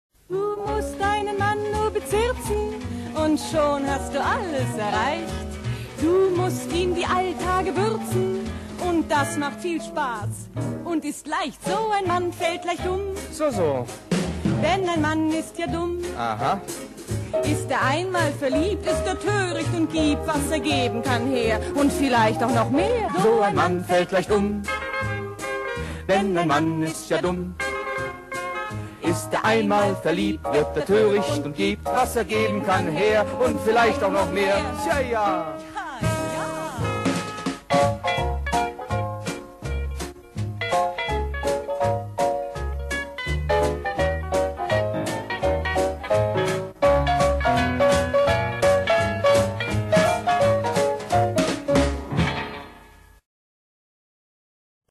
Rundfunkmusical (Hörspiel)